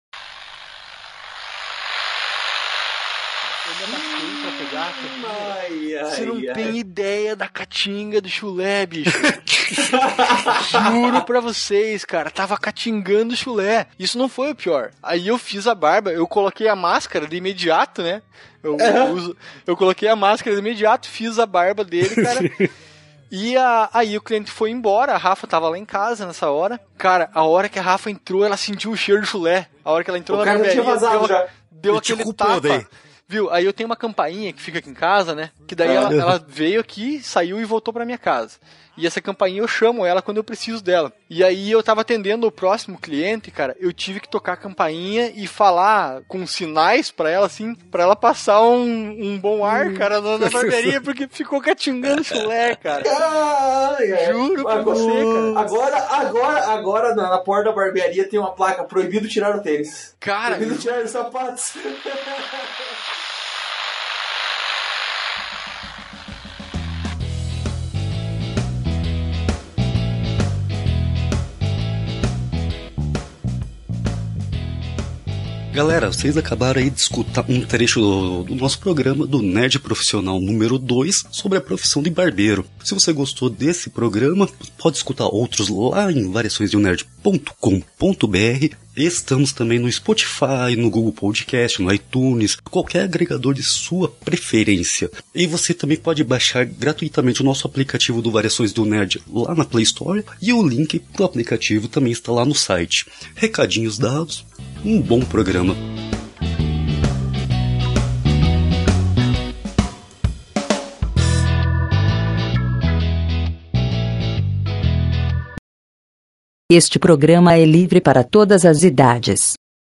batemos um papo